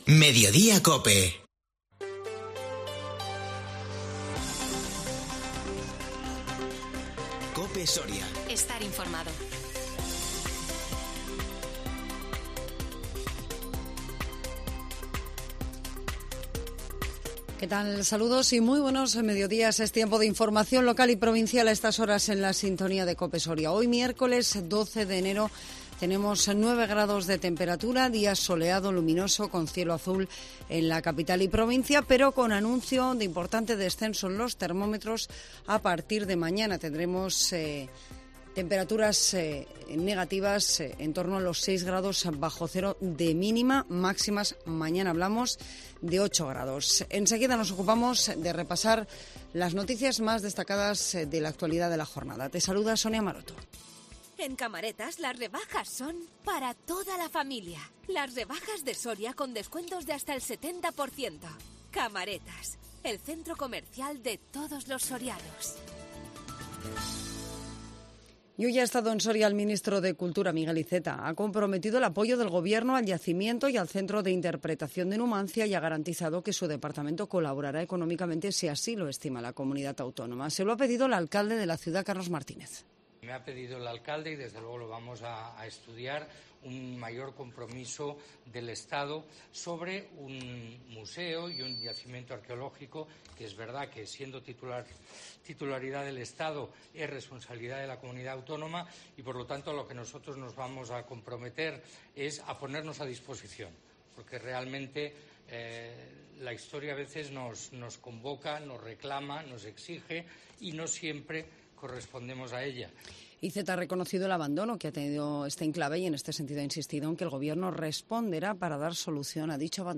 INFORMATIVO MEDIODÍA 12 ENERO 2021